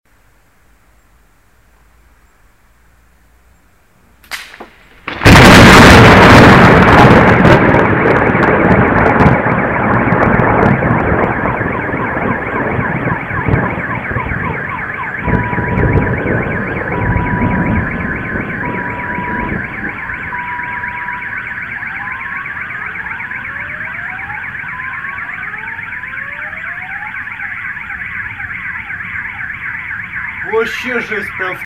Громкий звук грома молнии грозы и сигнализации машин в городе